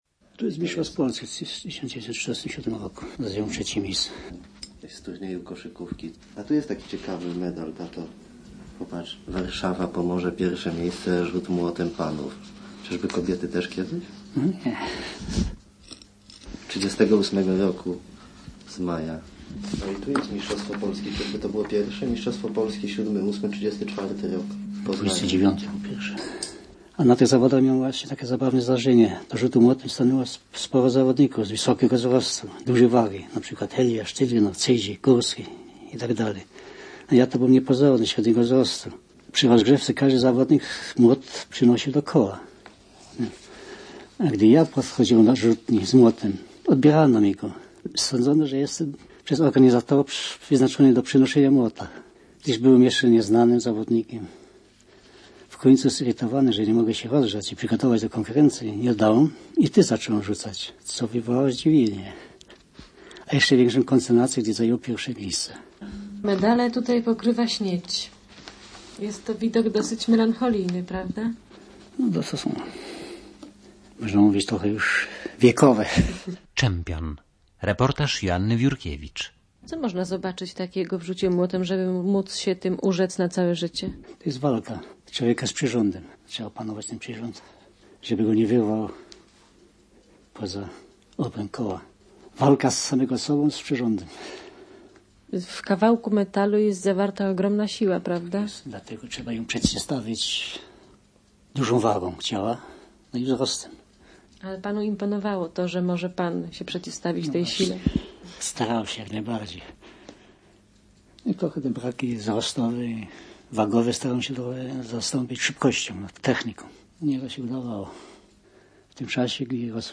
W roku 1979 udzielił wywiadu radiowego dla Radia PiK .